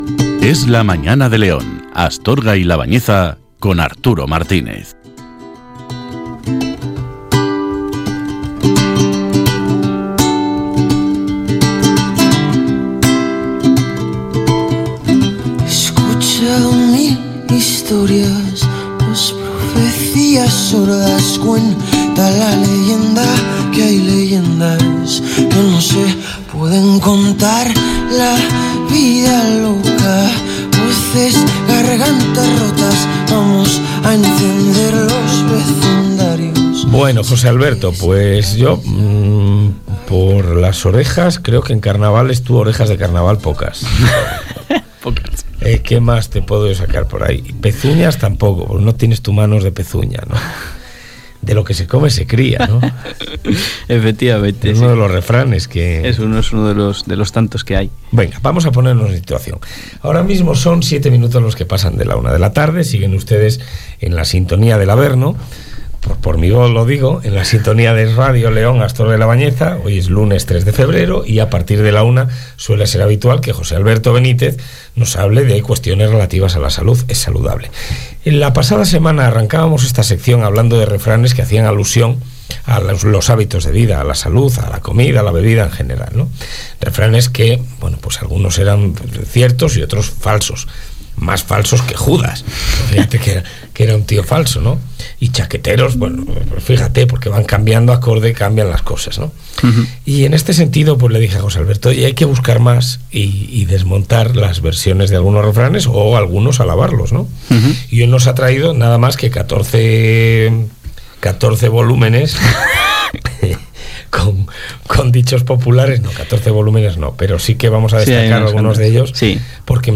Hoy os traigo el centésimo nonagésimo noveno programa de la sección que comenzamos en la radio local hace un tiempo y que hemos denominado Es Saludable, en el programa Es la Mañana de León, Astorga y La Bañeza en EsRadio.